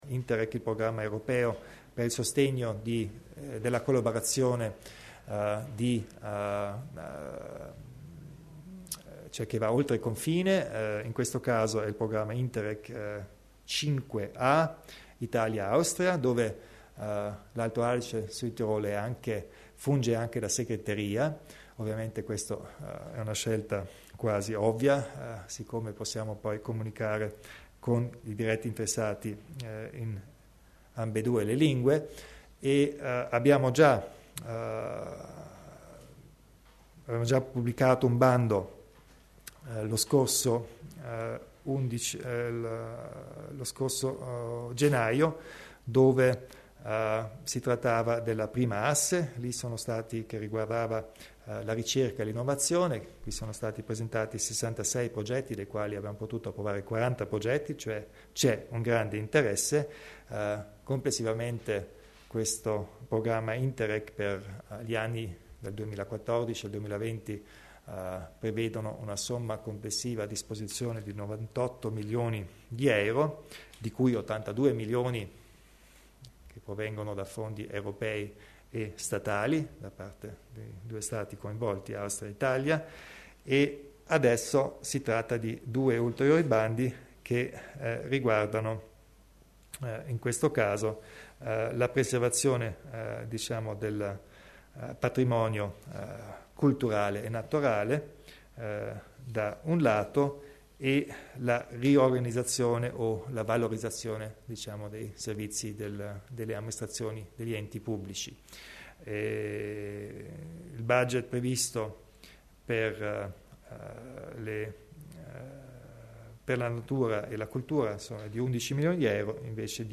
Il Presidente Kompatscher elenca i progetti Interreg